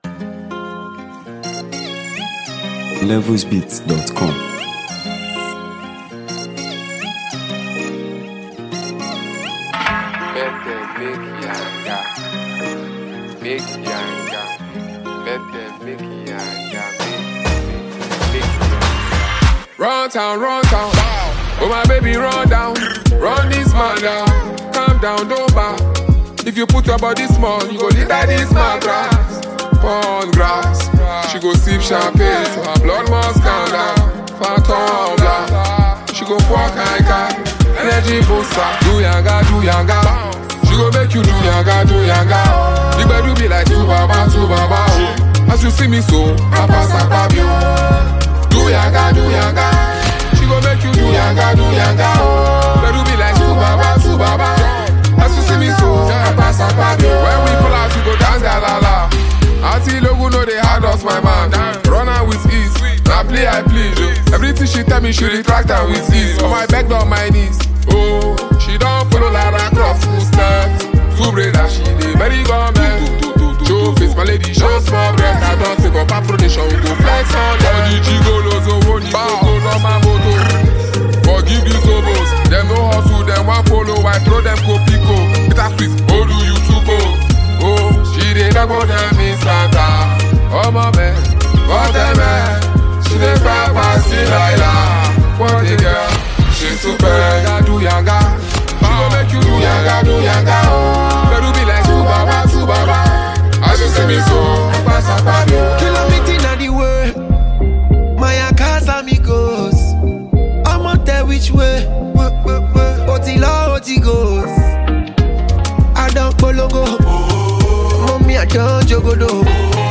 If you’re a lover of vibrant Afrobeat and dancehall fusion